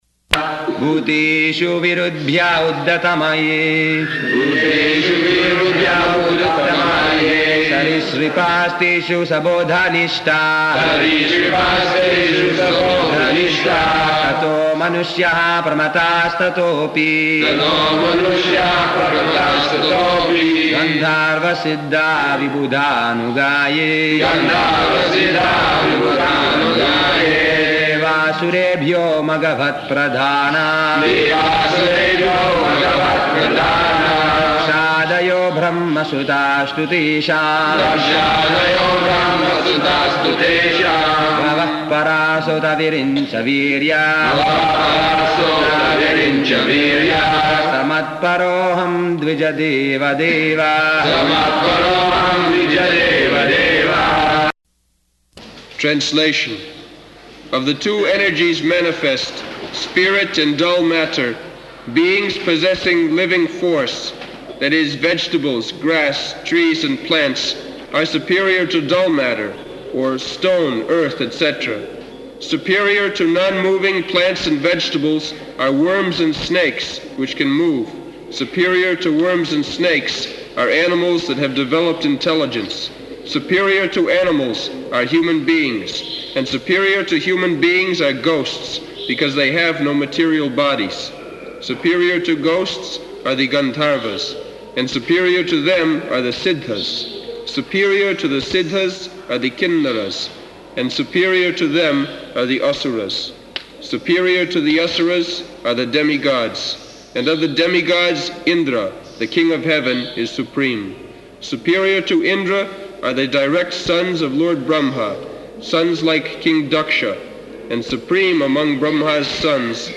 November 9th 1976 Location: Vṛndāvana Audio file
[leads chanting of verse, etc.]